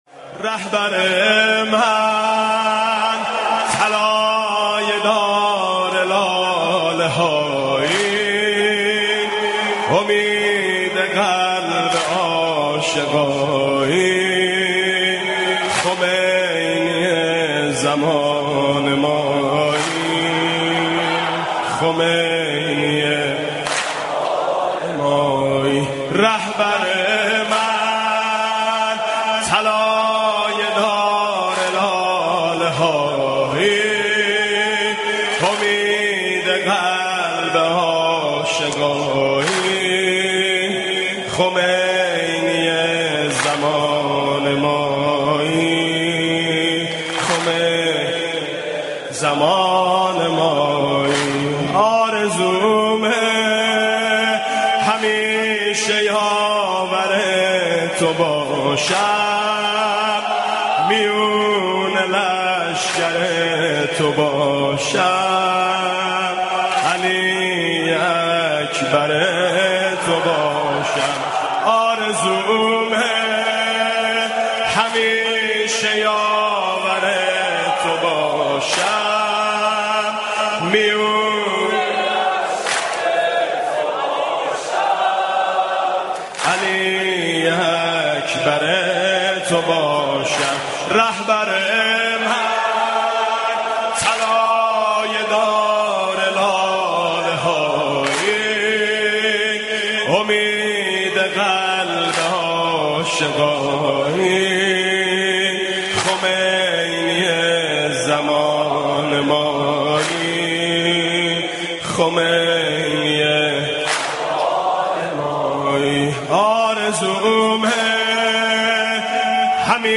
مداحي زيبا در وصف امام خامنه اي